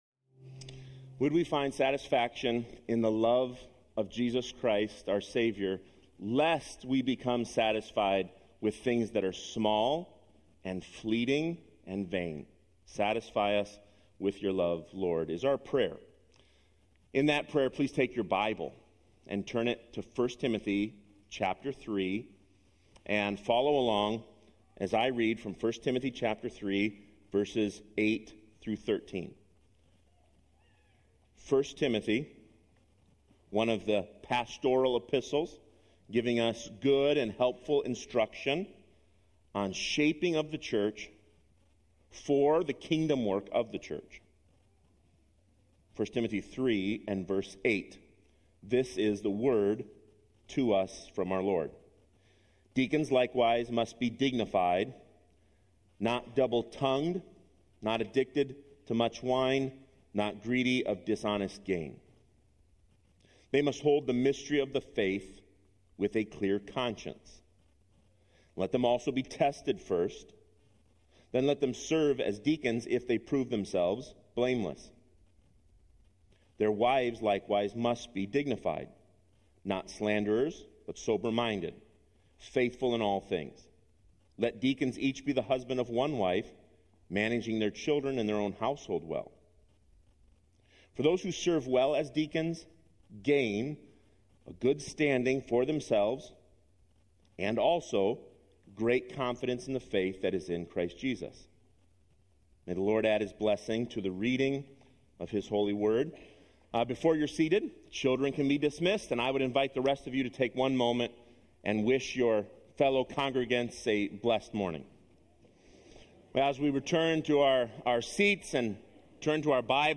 7-20-25-IBC-Sermon.mp3